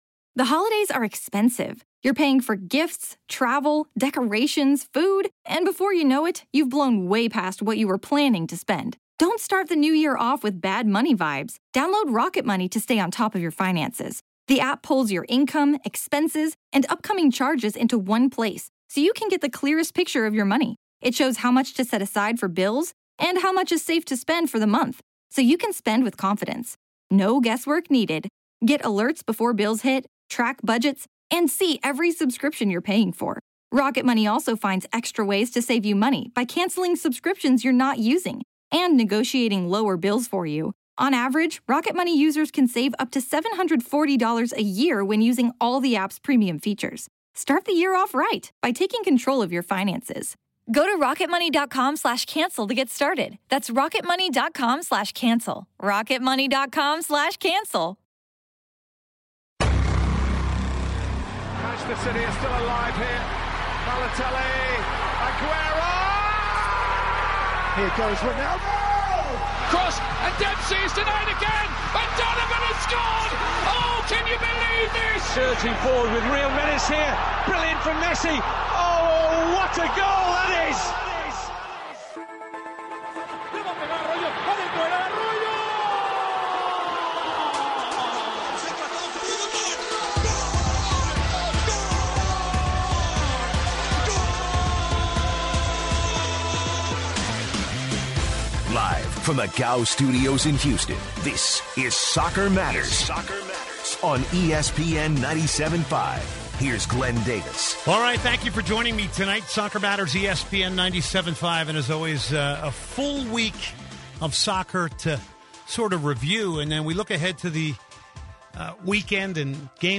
Callers weigh in on Barcelona, Dynamo over Portland, Orlando City SC, and the pressure at FC "Hollywood" Bayern Munich. The hour finishes with Liverpool's Steven Gerrard's emotional words with supporters at Anfield in his final match there.